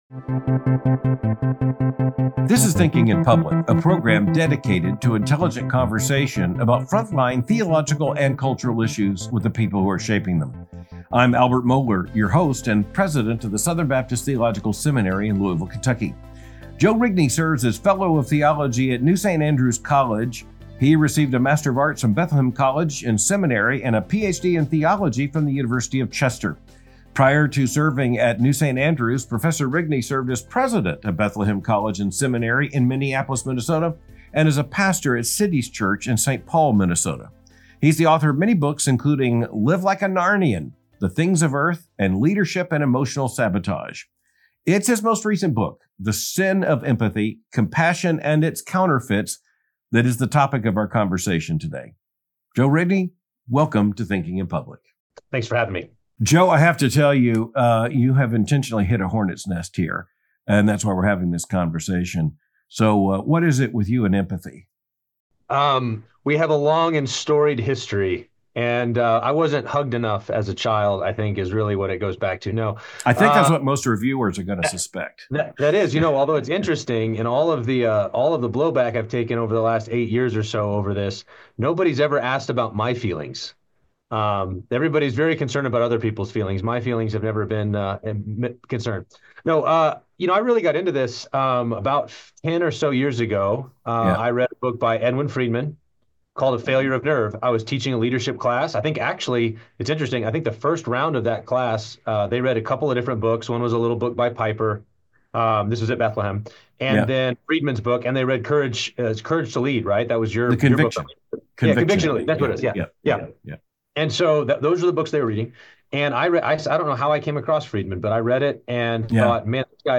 The Sin of Empathy — A Conversation
This is Thinking In Public, a program dedicated to intelligent conversation about frontline theological and cultural issues with the people who are shaping them.